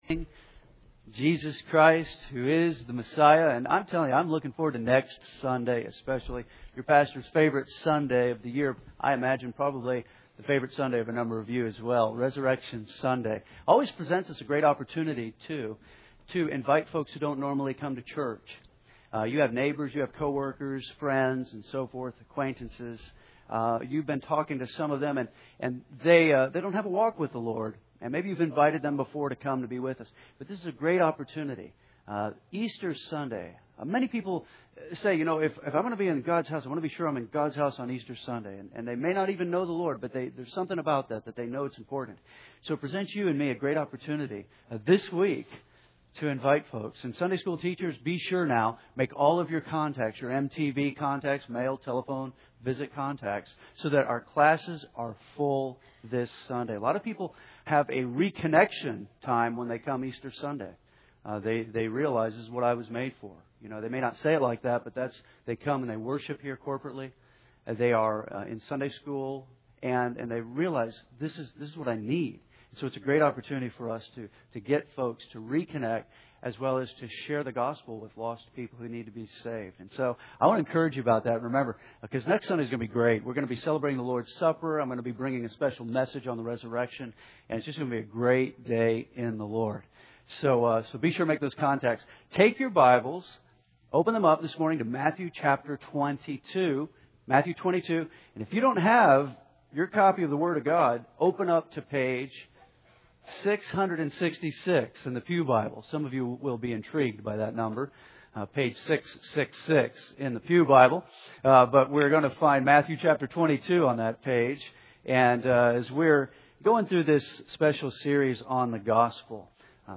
team preaching